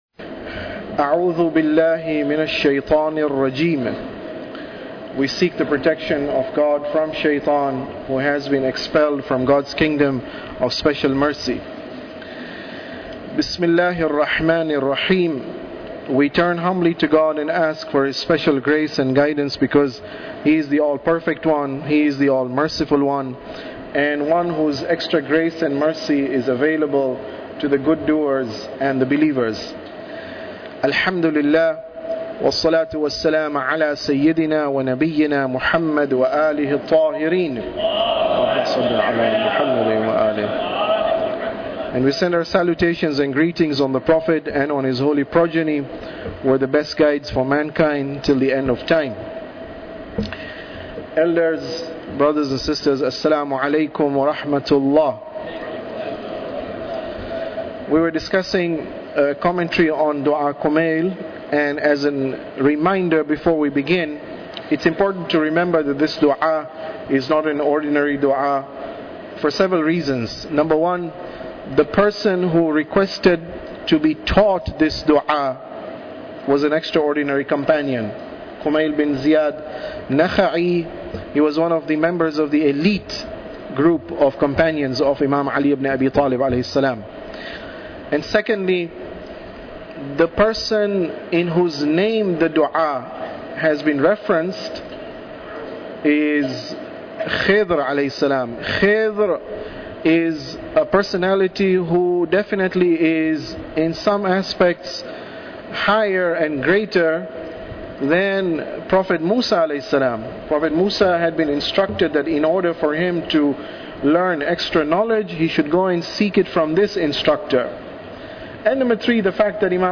Tafsir Dua Kumail Lecture 21